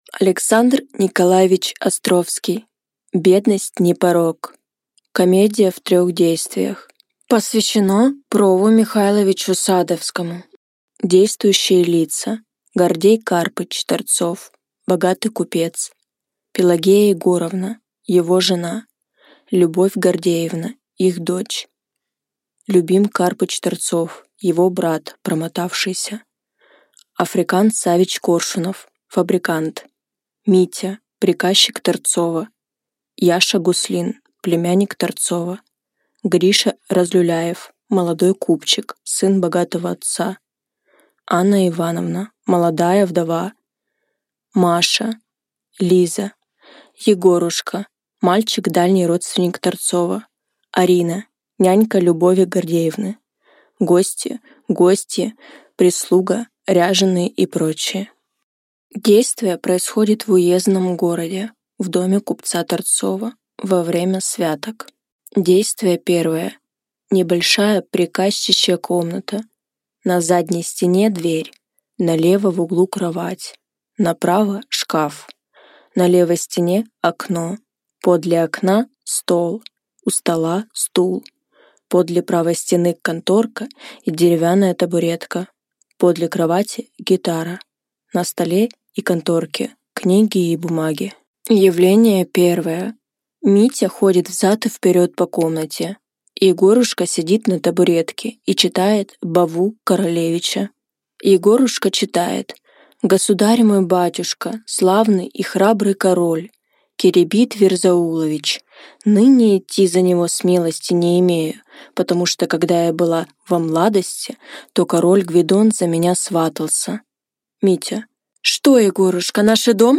Аудиокнига Бедность не порок | Библиотека аудиокниг